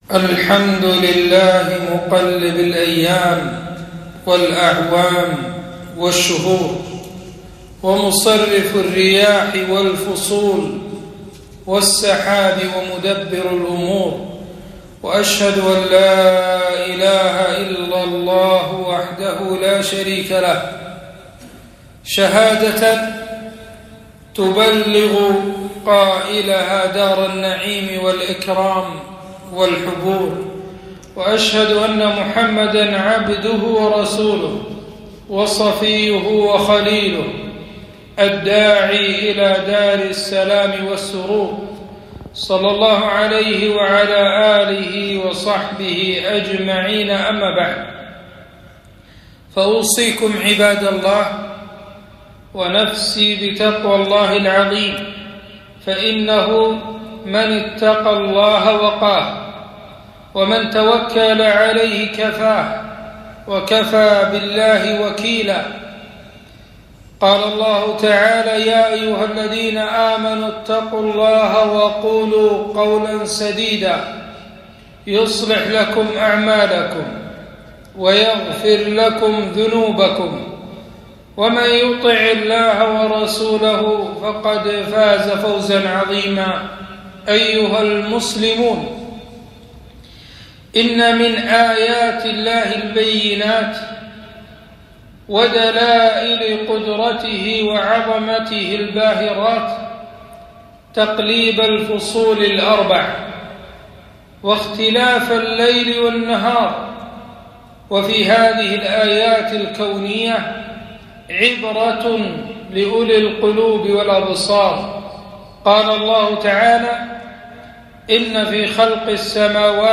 خطبة - حر الصيف موعظة وذكرى